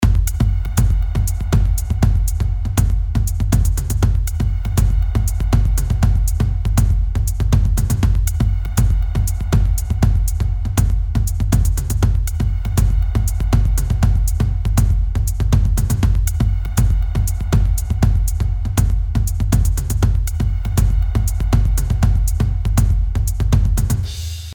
מקצב.mp3